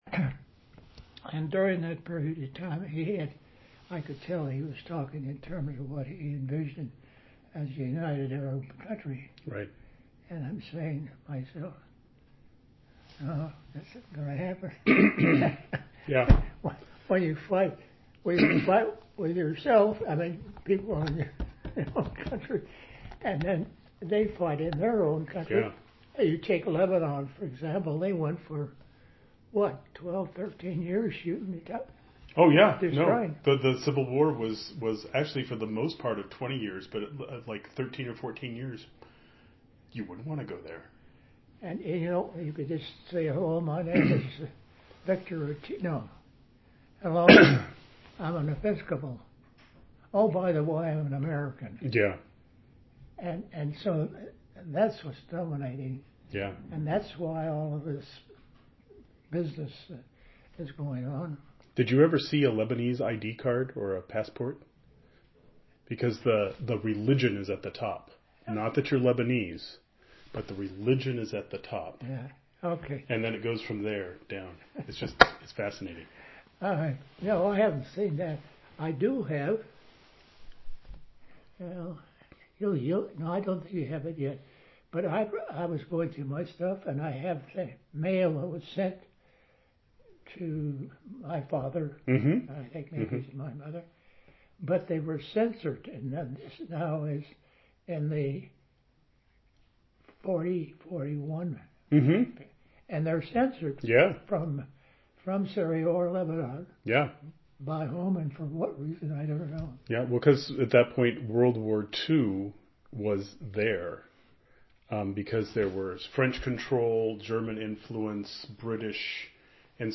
4aa9de6f940235b7c41641b99acc77a56c54b2a2.mp3 Title Governor Vic Atiyeh oral history interview, Jan 2, 2014 Description An interview of former Oregon Governor Victor Atiyeh, recorded on January 2, 2014.
His voice is relatively faint and thin compared to earlier recordings. Key points include: Atiyeh's views on Arab unity, the Lebanese Civil War, and the influence of religious identity in Lebanon; Atiyeh's governorship, highlighting his relationships with elected officials, including Mark Hatfield and Bob Packwood; Atiyeh's approach to governance, emphasizing practical solutions and respect for statewide officials; the Rajneesh movement, his strategy of not engaging with them, and the broader implications for religious freedom and public order.